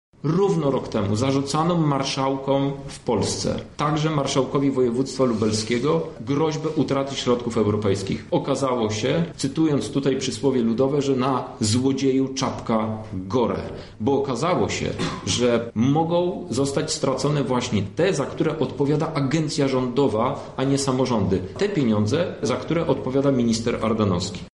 Działacze partii twierdzą, że już dawno zwracali uwagę na nieprawidłowości przyznawaniu dopłat rolnikom. Przez złe zarządzanie część środków będzie musiała być zwrócona Unii Europejskiej – tłumaczy lider lubleskich ludowców, Krzysztof Hetman: